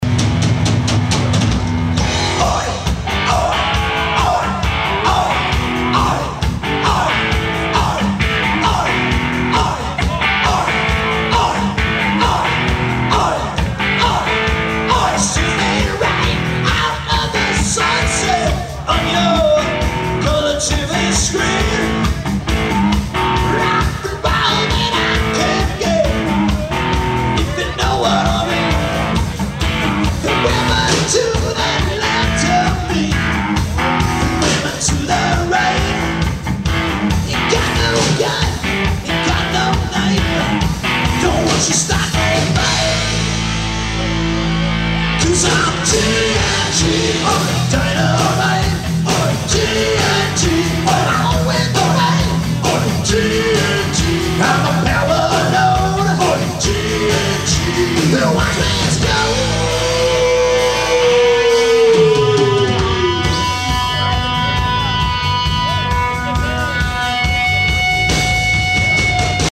live performance sound